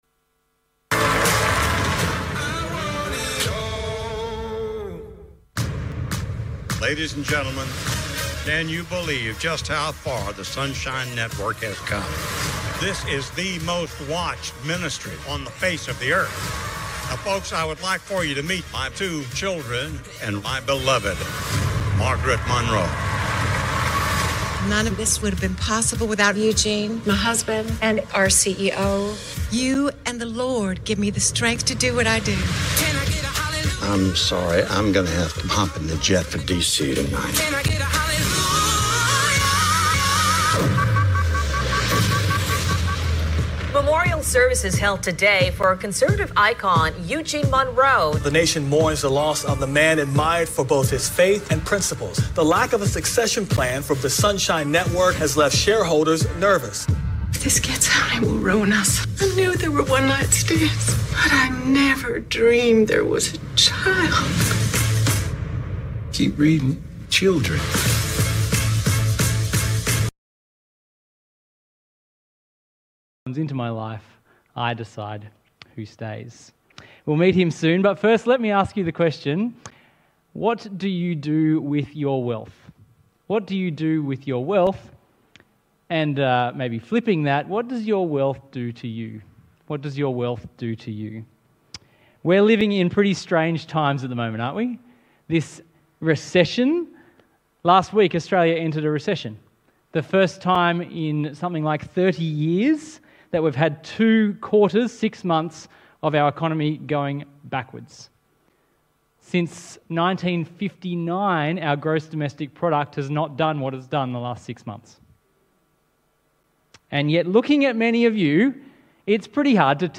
There were once again some technical difficulties, so the audio briefly breaks up after 1:10.
Talk Type: Bible Talk